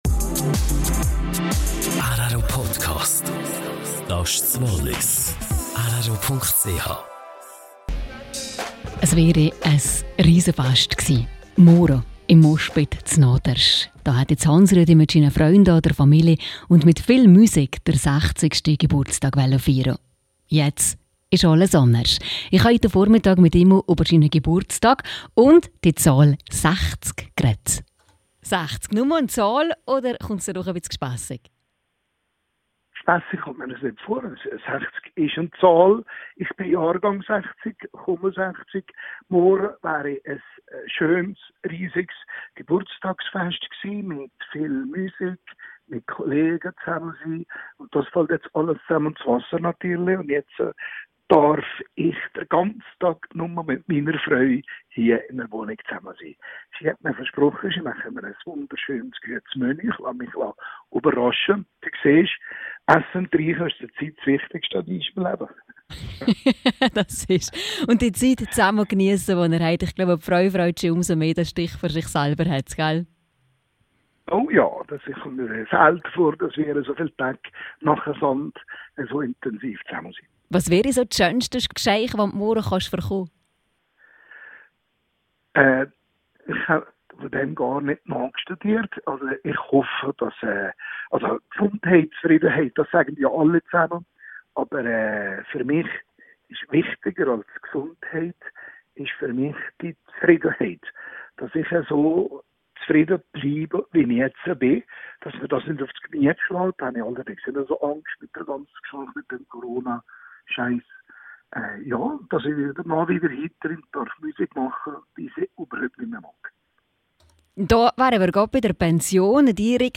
rro-Interview